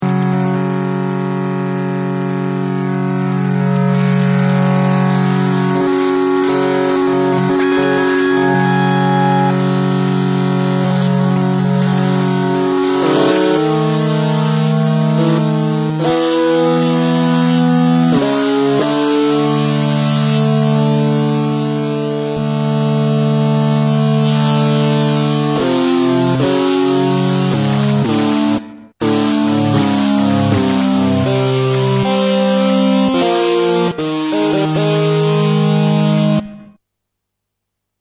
Roland JX-10 Polyphonic Synthesizer.
Mean1 - An example showing that the JX-10 can do more than pretan pads. For this type of sound you have to use the hard sync capabilities